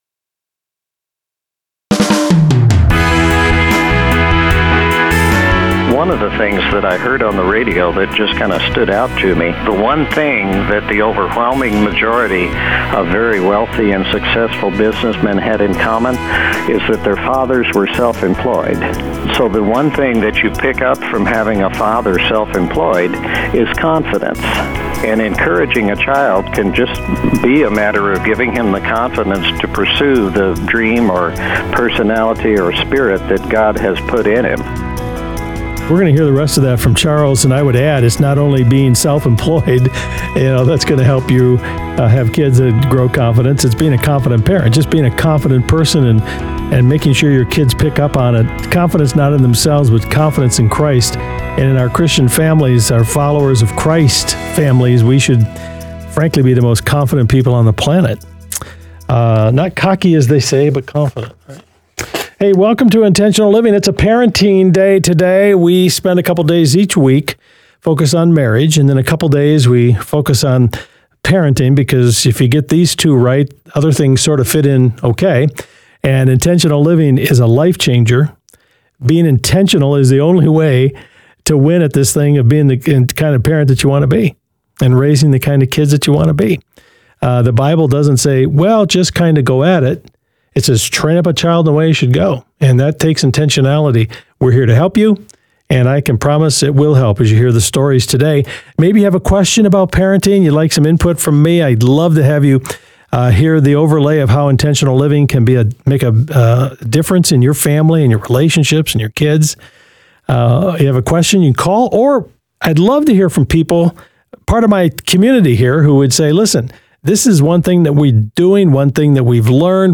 Intentional Living is a nationally-syndicated program on more than 250 radio stations including Family Life Radio.